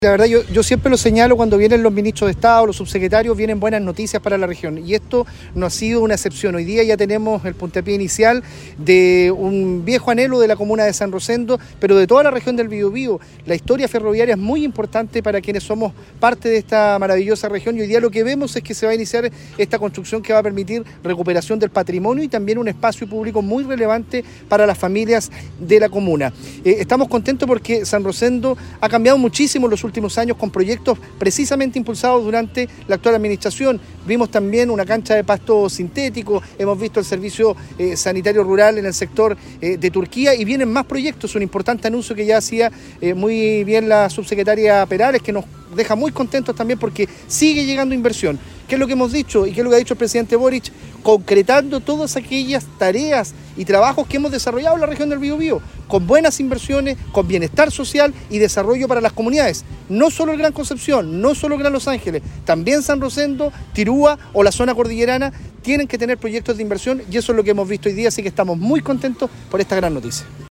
El delegado presidencial regional, Eduardo Pacheco, presente en la ceremonia, destacó buenas noticias para San Rosendo.